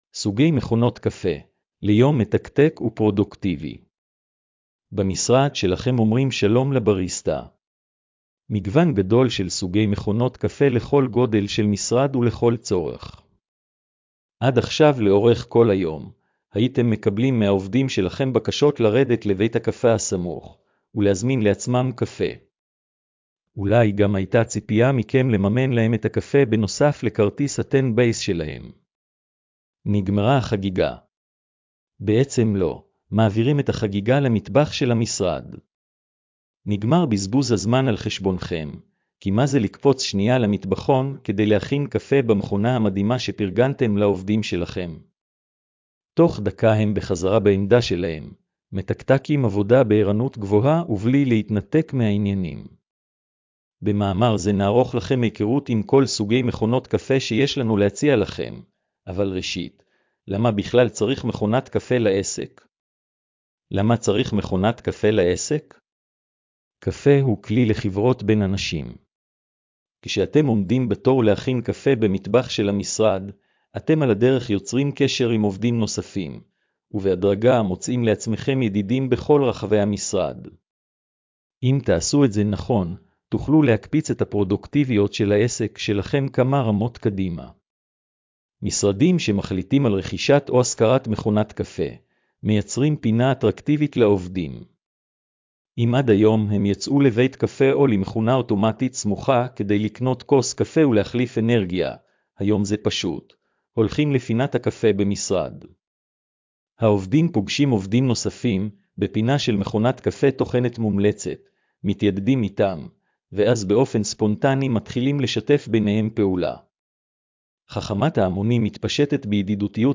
הקראת מאמר לבעלי מוגבלות: